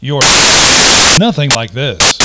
In 1995, Wired magazine's AOL forum located Elwood Edwards -- whose voice recorded the sound file "You've Got Mail" -- and had him record ten additional sound files.